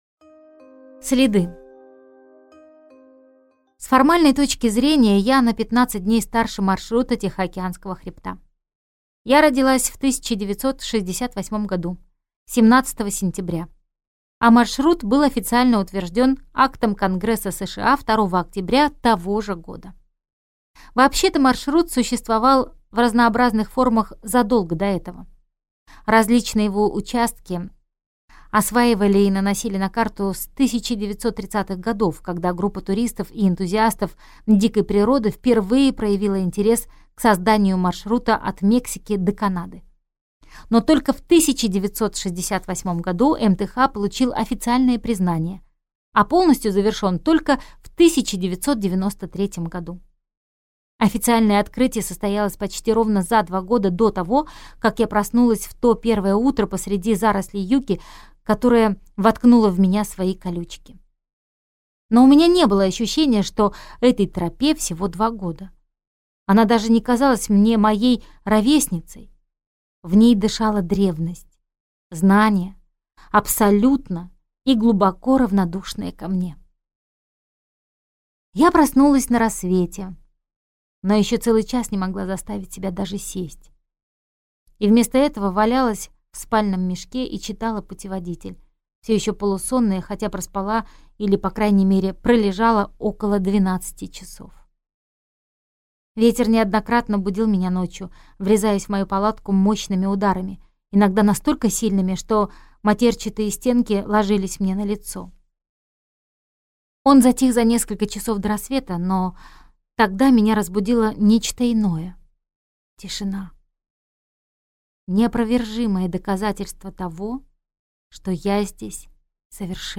Аудиокнига Дикая. Опасное путешествие как способ обрести себя | Библиотека аудиокниг